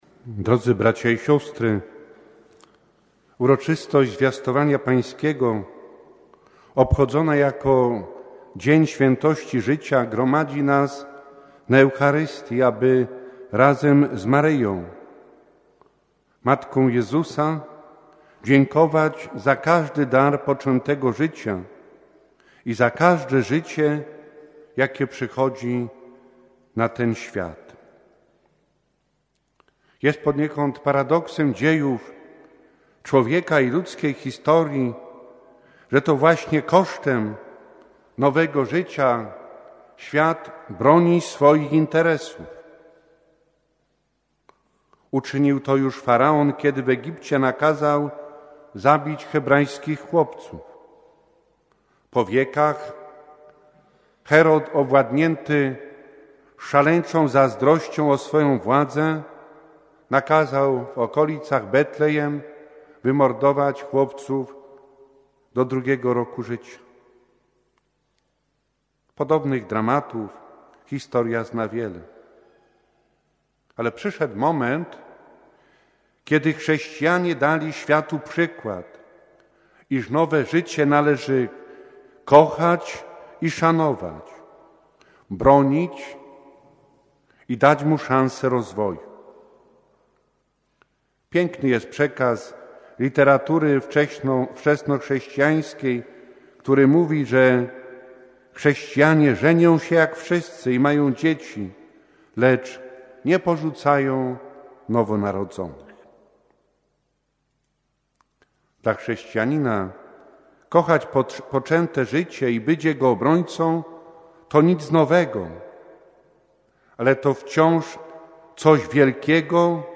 Dziś w liturgii Kościoła obchodzimy Uroczystość Zwiastowania Pańskiego, a zarazem dzień Świętości Życia, dlatego o godz. 18.00 sprawowana była uroczysta Msza Święta pod przewodnictwem ks. bpa Jana Piotrowskiego. Ks. bp Jan wygłosił również homilię podkreślając temat wartości życia.
Homilia ks. bpa Jana Piotrowskiego